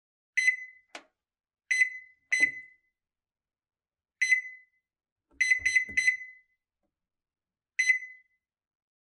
Звуки микроволновой печи